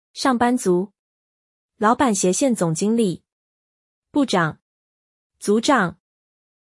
会社員 上班族 shàng bān zú 「出勤する人」の意。オフィスワーカー全般を指す。